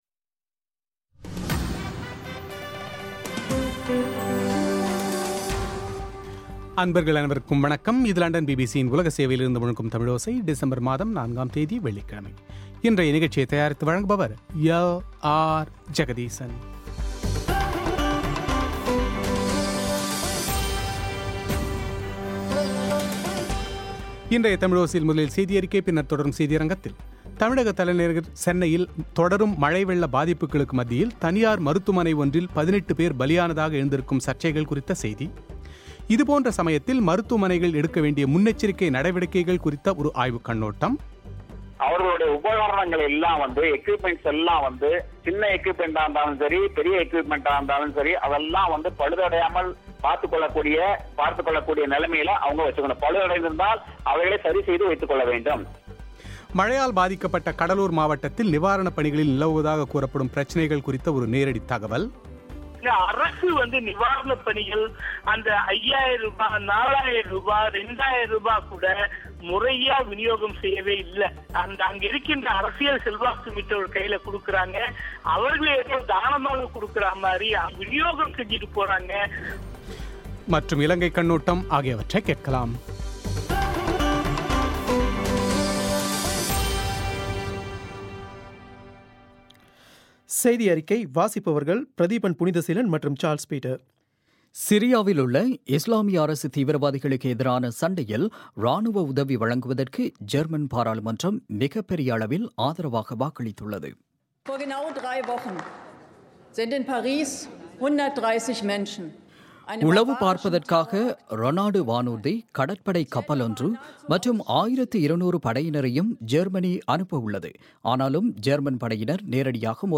கடும் மழை பாதித்த சென்னை நகரில் கடந்த இரு நாட்களாக அத்தியாவசிய பொருட்களான, பால் , குடிநீர் போன்றவைகளுக்கே தட்டுப்பாடு நிலவுவதாக வரும் செய்திகளின் உண்மை நிலவரம் என்ன என்பது குறித்த செவ்வி;